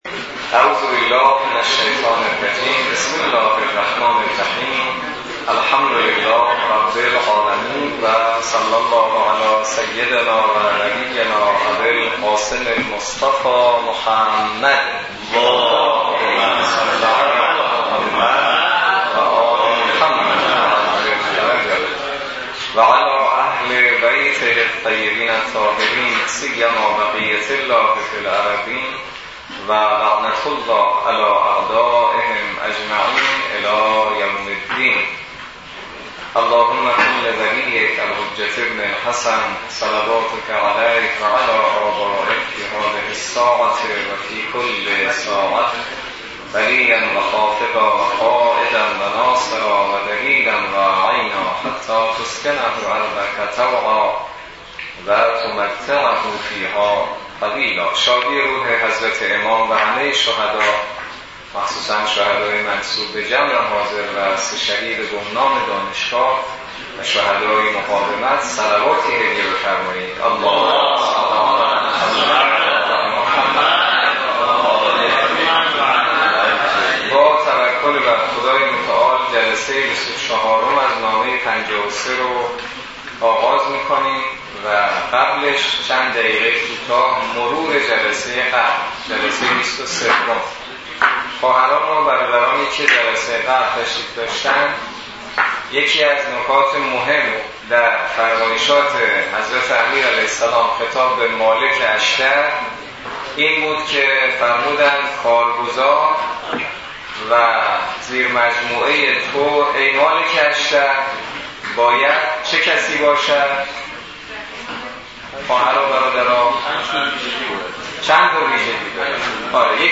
برگزاری بیست و چهارمین جلسه مباحثه نامه ۵۳ نهج البلاغه توسط امام جمعه کاشان در دانشگاه کاشان